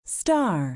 Index of /phonetones/unzipped/LG/KE990-Viewty/Keytone sounds/Bee-beep